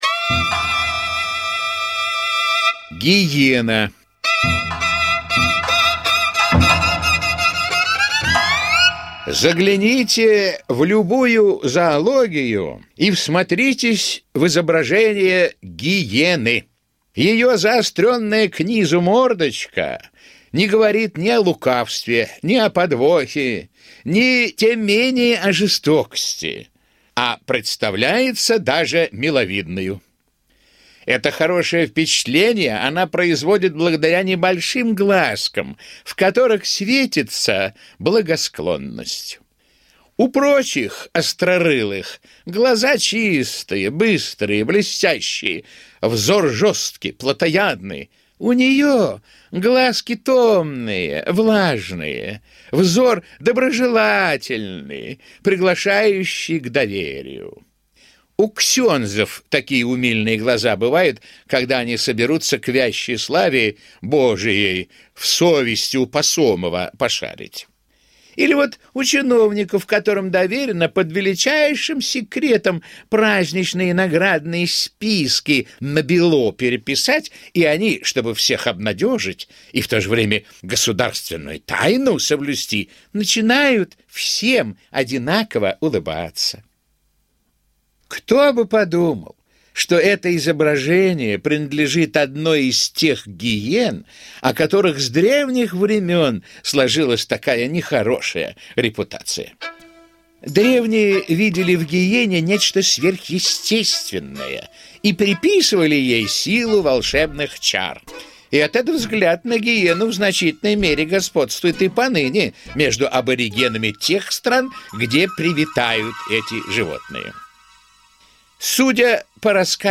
Гиена - аудиосказка Михаила Салтыкова-Щедрина - слушать онлайн